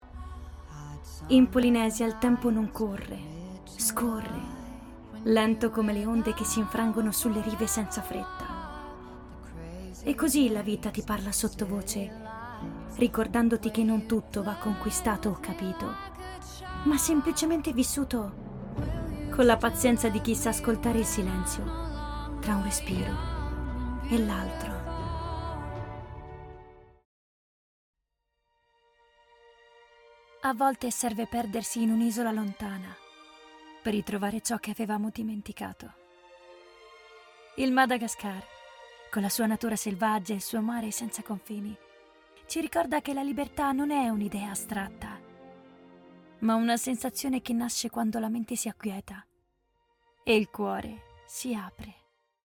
promo aziendale
caratterizzazioni varie da bambini, ragazzini a donne